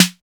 808 DEEP SN.wav